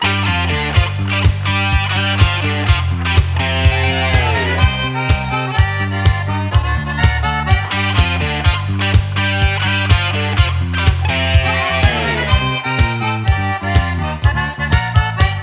• Качество: 320, Stereo
позитивные
громкие
без слов
электрогитара
гармонь
русский рок